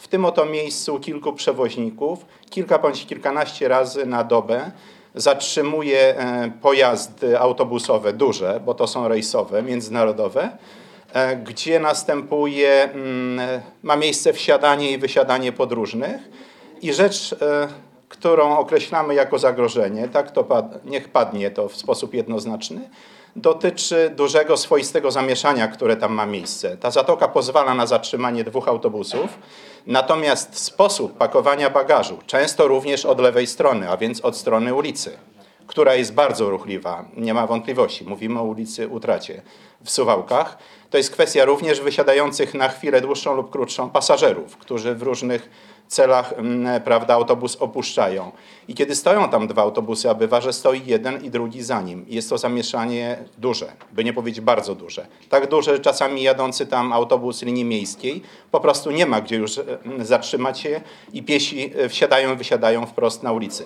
O bezpieczeństwo podróżnych i kierowców autobusów rejsowych upomniał się na ostatniej sesji Rady Miejskiej Zdzisław Przełomiec, przewodniczący gremium. Chodzi o to, że autobusy parkują w zatoczce autobusowej przy ruchliwej ulicy Utrata, vis-a-vis dworca PKS.
Zdzisław-Przełomiec-przewodniczący-gremium.mp3